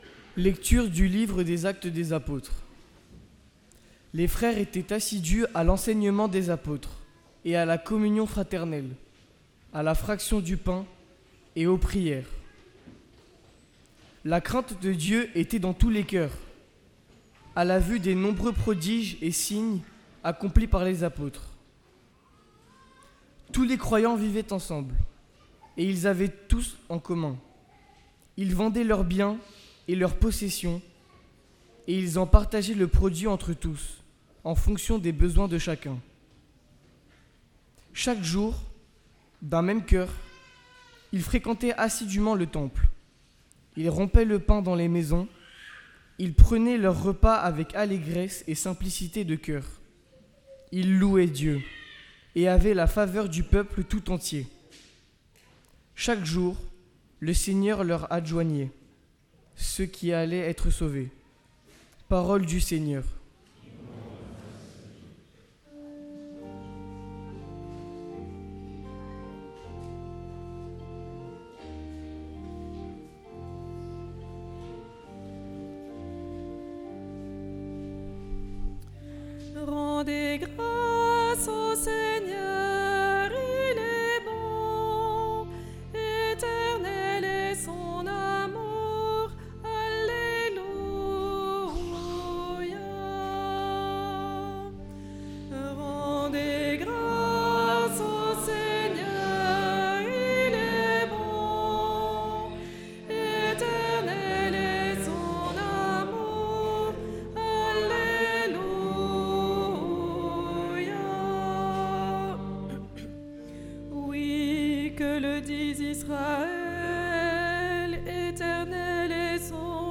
Parole de Dieu & Sermon du 12 avril 2026